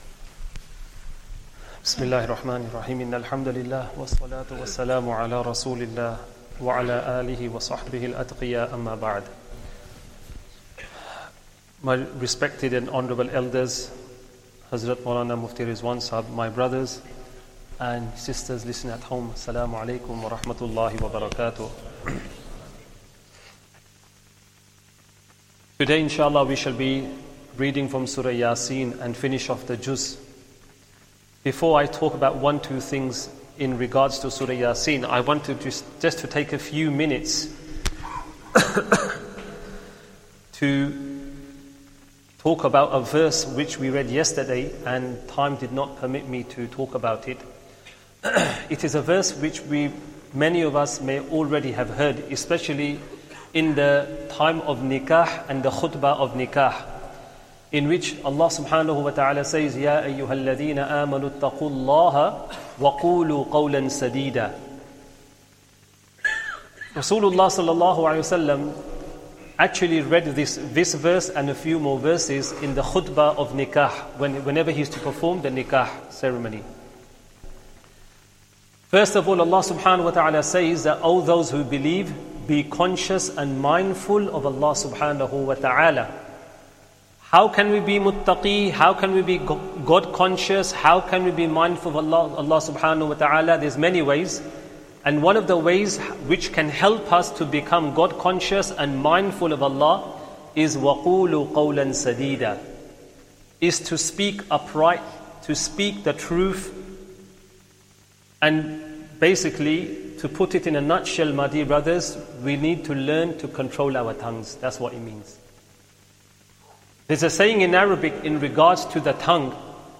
Esha Talk & Jammat